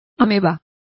Complete with pronunciation of the translation of amoebas.